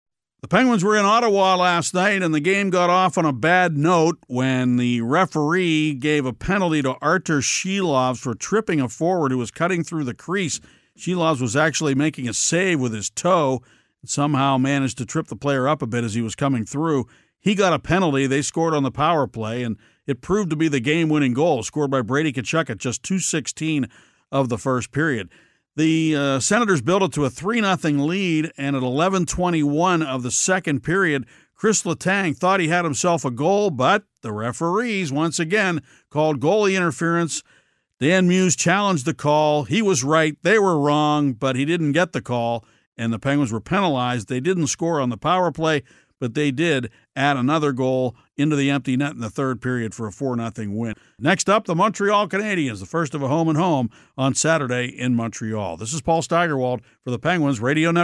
The Penguins battled both the Ottawa Senators and the referees last night, and they lost to both of them. Paul Steigerwald has the recap of a seventh straight Penguins loss.